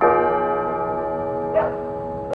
• BELL TOLL WITH BARK.wav
BELL_TOLL_WITH_BARK_u78.wav